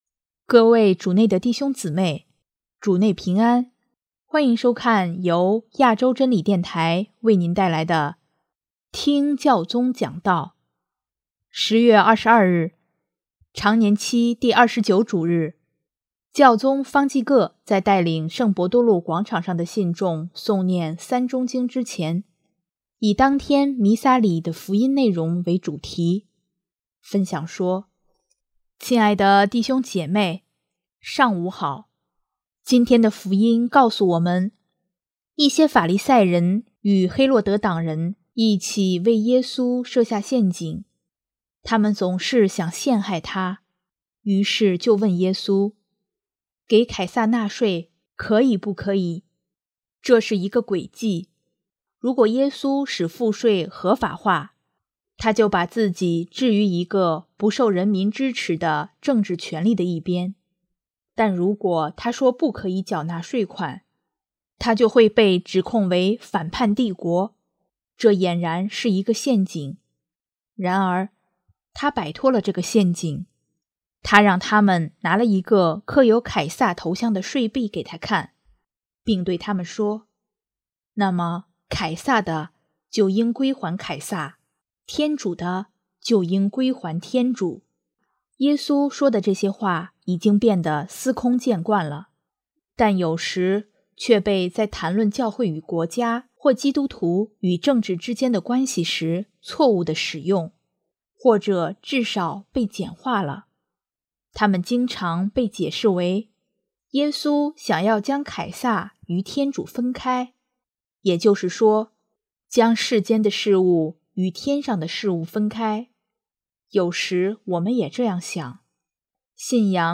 10月22日，常年期第二十九主日，教宗方济各在带领圣伯多禄广场上的信众诵念《三钟经》之前，以当天弥撒礼仪的福音内容为主题，分享说：